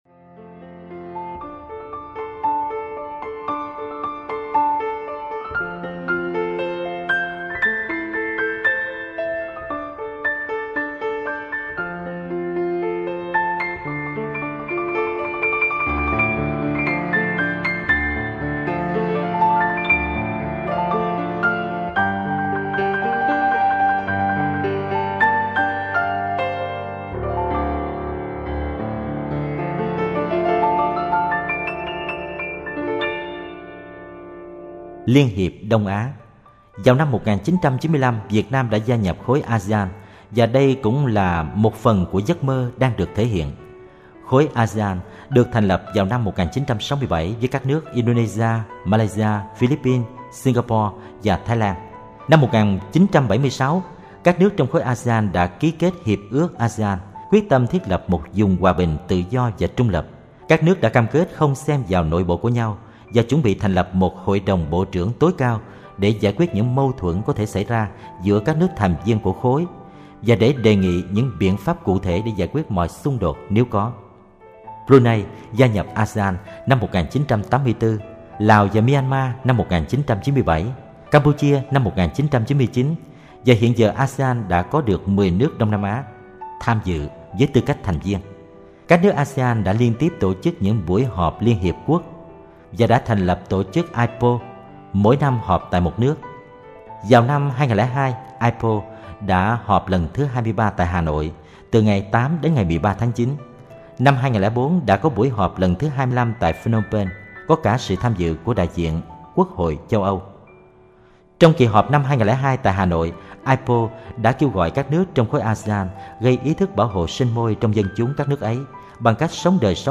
Sách nói Tuổi Trẻ Tình Yêu Và Lý Tưởng - Thích Nhất Hạnh - Sách Nói Online Hay